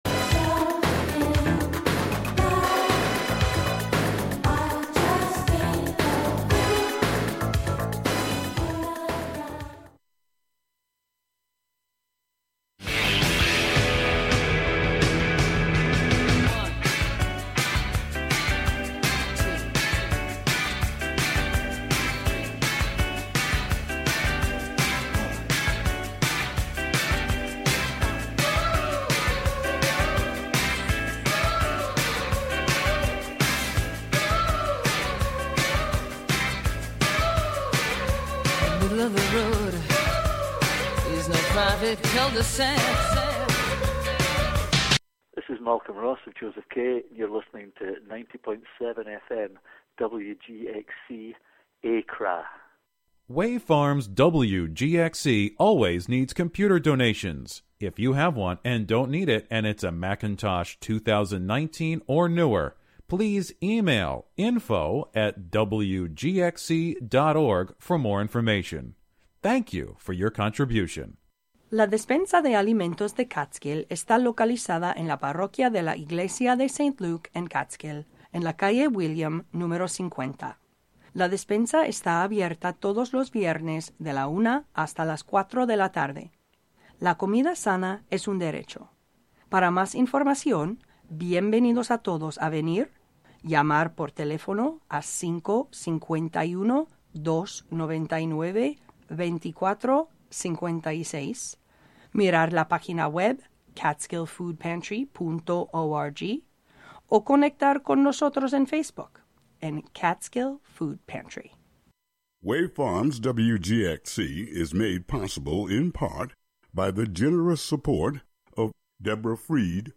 A SPLEN-DID hour of sound and music inspired by the recombinant qualities of food with occasional conversations about milk.